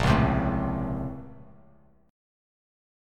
A#mM9 chord